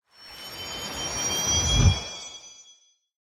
Minecraft Version Minecraft Version 1.21.5 Latest Release | Latest Snapshot 1.21.5 / assets / minecraft / sounds / block / trial_spawner / about_to_spawn_item.ogg Compare With Compare With Latest Release | Latest Snapshot
about_to_spawn_item.ogg